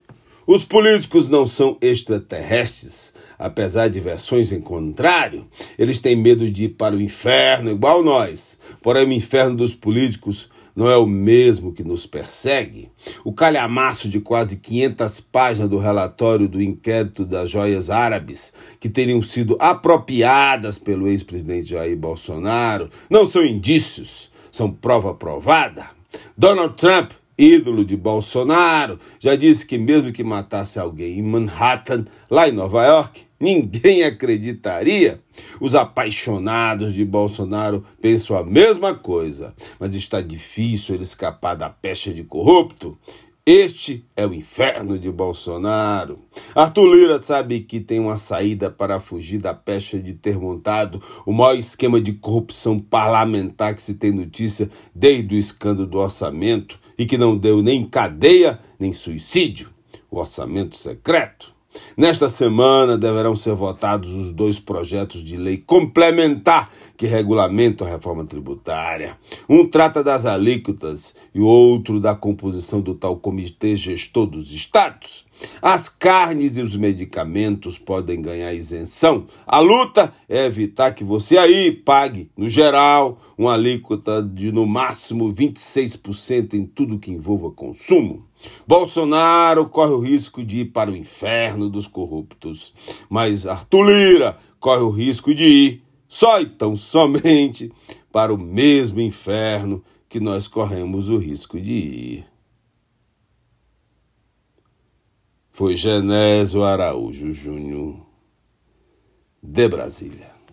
Comentário desta terça-feira (09/07/24)
direto de Brasília.